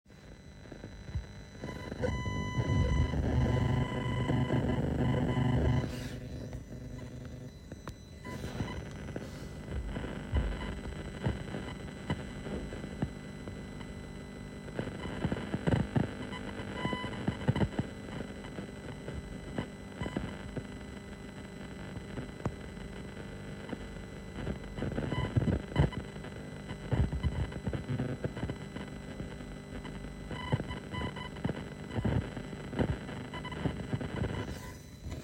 Comme vu dans le forum, je vous joins en pièce jointe le bruit que j'ai dans mes enceintes.
Bruit dans les enceintes.mp3